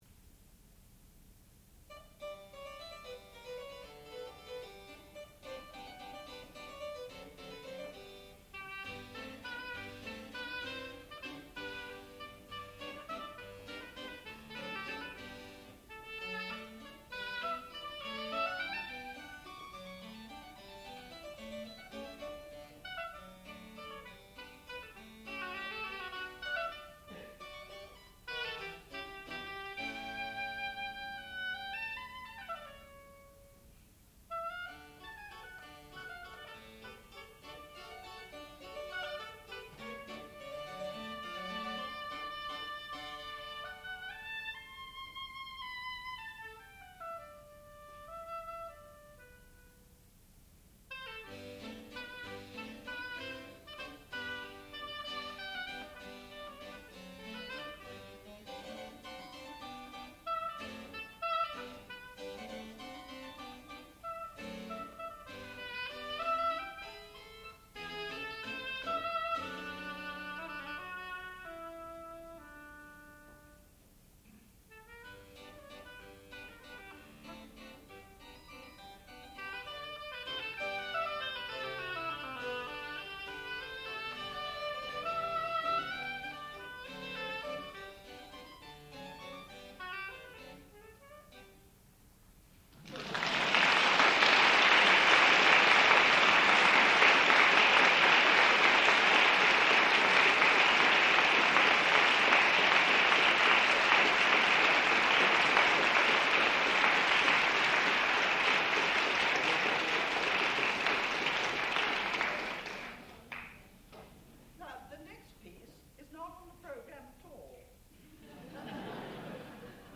sound recording-musical
classical music
harpsichord and Evelyn Barbirolli